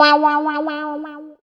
110 GTR 1 -R.wav